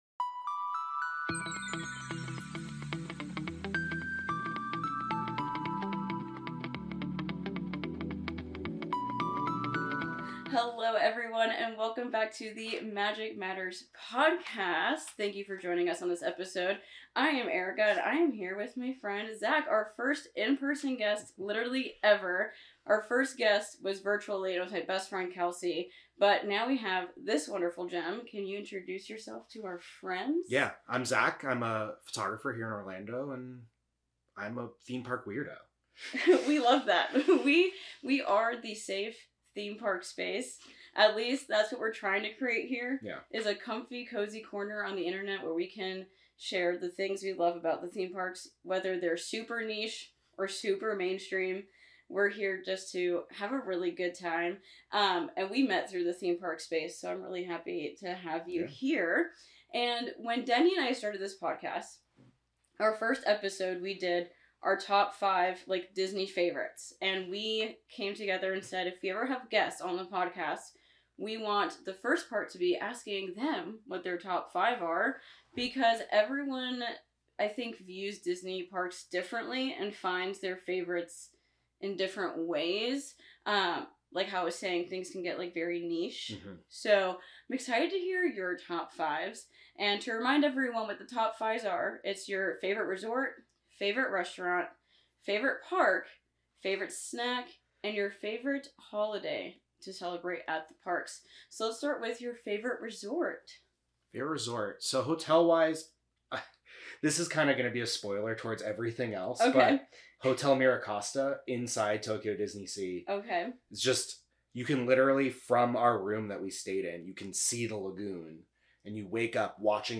Get ready for a magical conversation as we welcome our very first guests to Magic Matters!